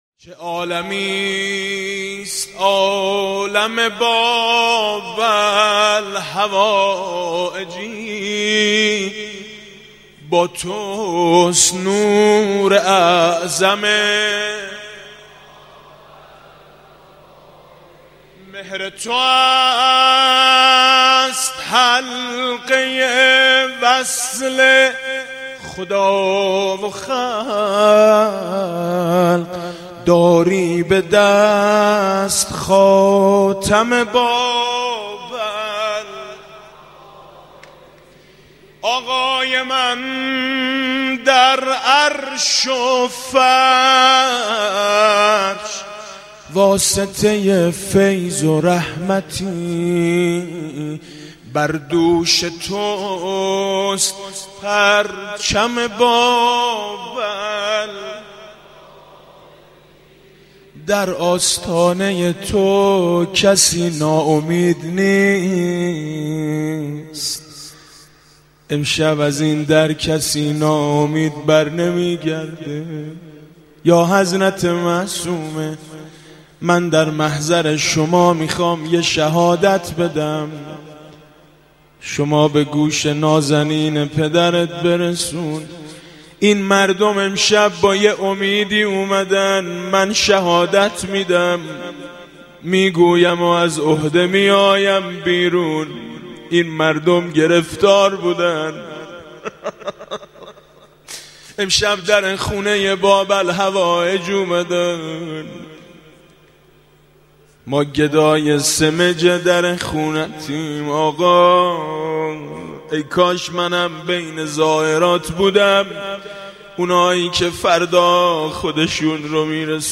روضه: ما سائلیم، سائل آقای کاظمین